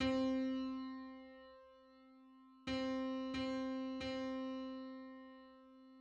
Centitone_on_C.mid.mp3